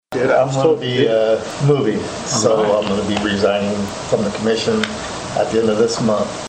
During Wednesday’s regular meeting, Second Precinct Commissioner Rodger Moyer made the following announcement.